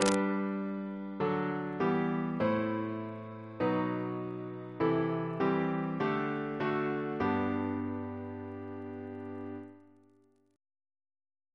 Single chant in G Composer: Stephen Elvey (1805-1860), Organist of New College, Oxford; George's brother Reference psalters: H1982: S178